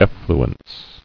[ef·flu·ence]